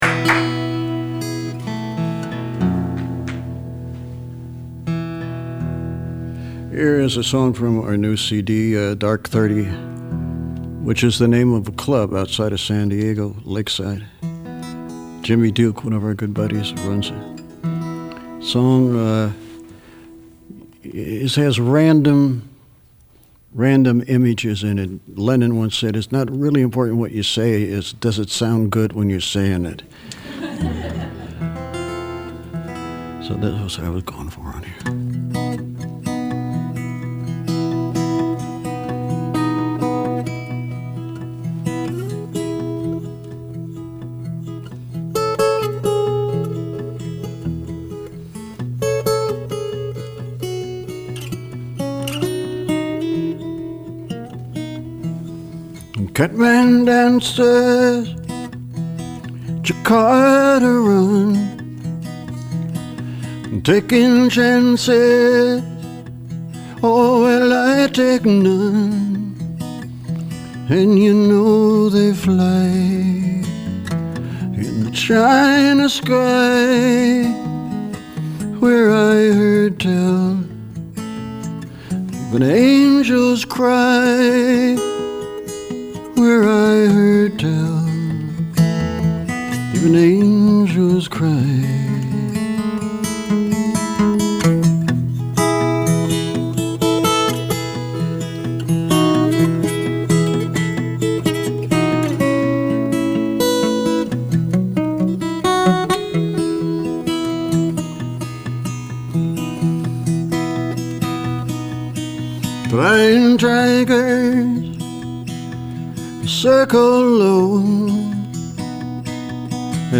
It just sounds good when he sings it.